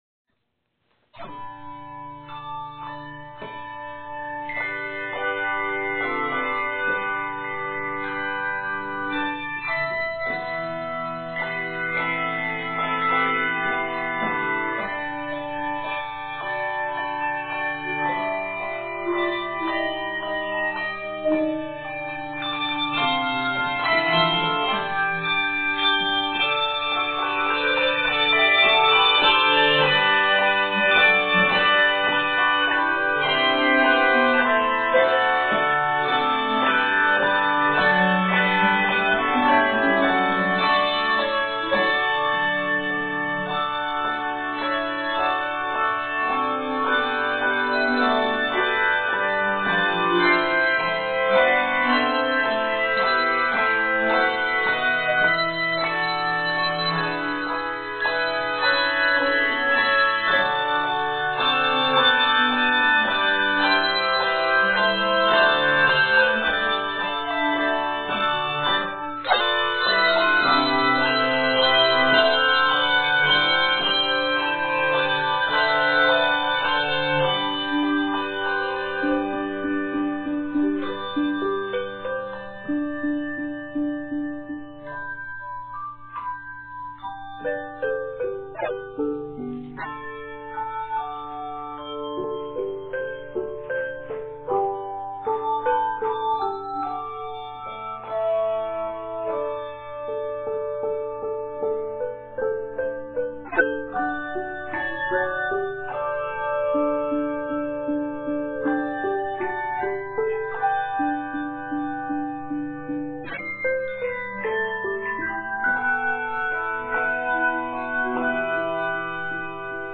Written in G Major and C Major, it is 116 measures.
Octaves: 3-5